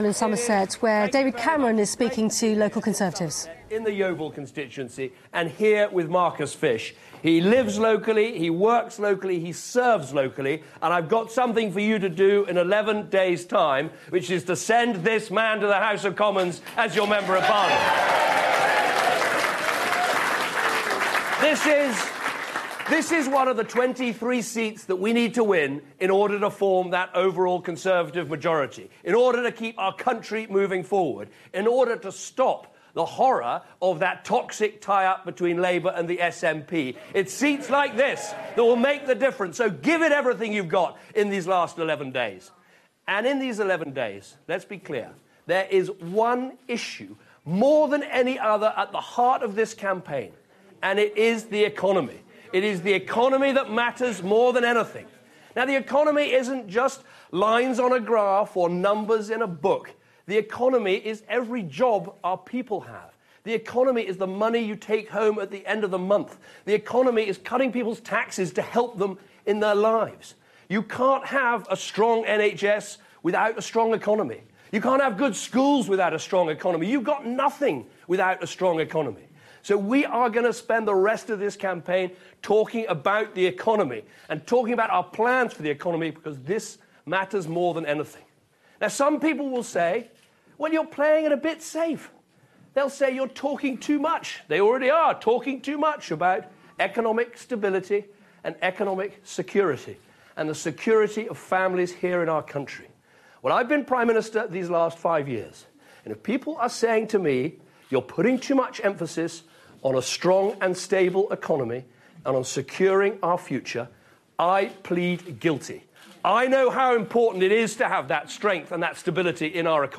'We can do it!' - David Cameron rallies Tory activists in Lib Dem-held Yeovil